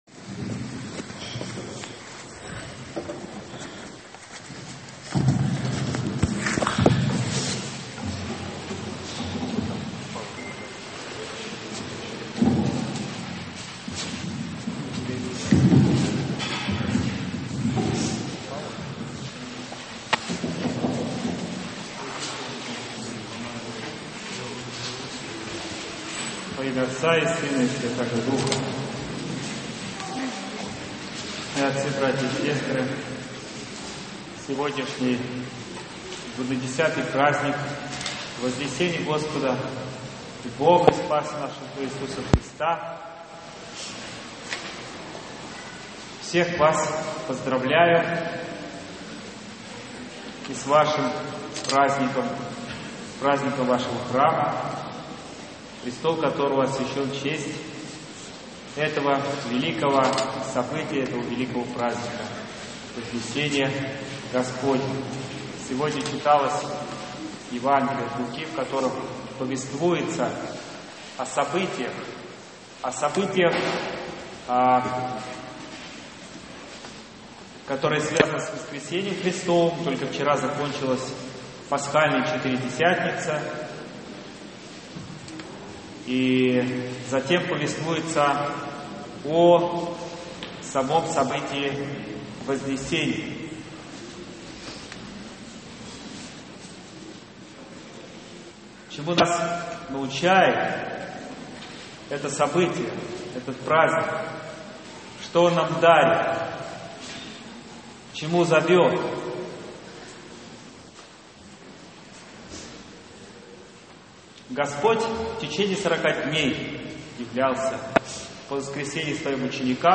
9 июня, в праздник Вознесения Господня, епископ Выксунский и Павловский Варнава совершил Божественную Литургию в Вознесенском соборе города Павлово.
В престольный праздник на службе молились многочисленные прихожане не только собора, но и других храмов Павловского благочиния.